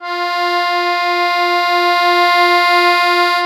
MUSETTE1.6SW.wav